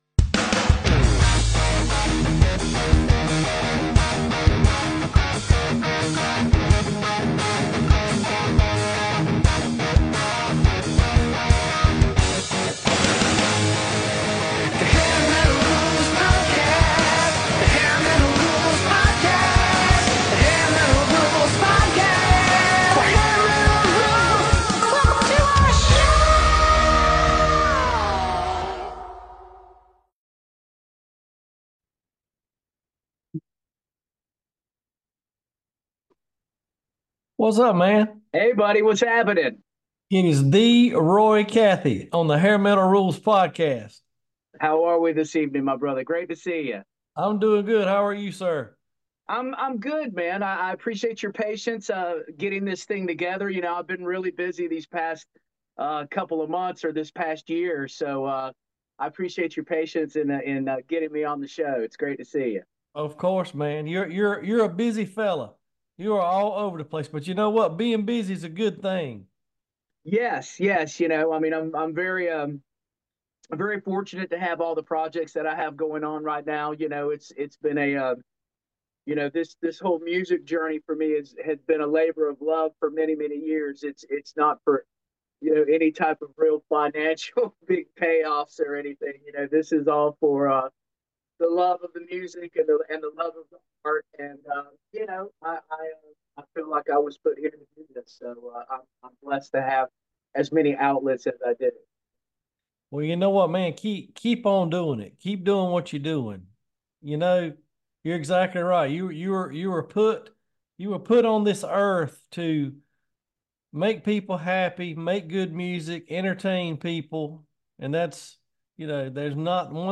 Music Interviews